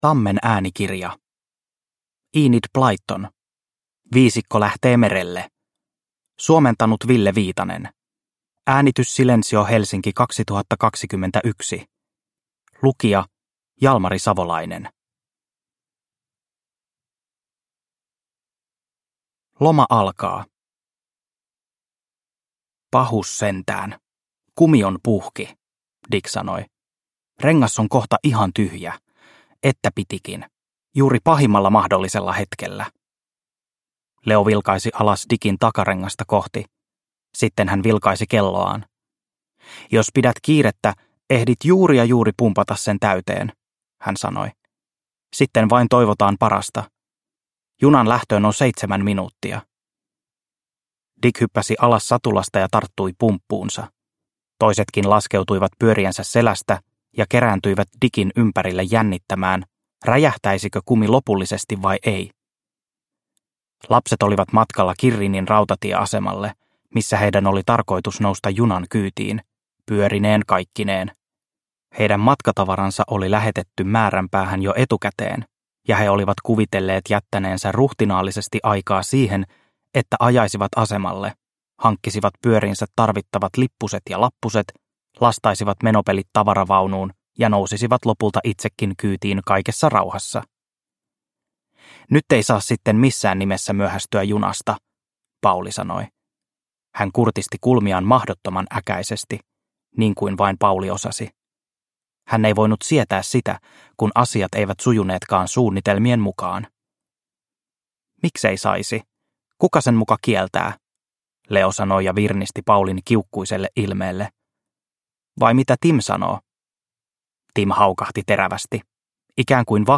Viisikko lähtee merelle – Ljudbok – Laddas ner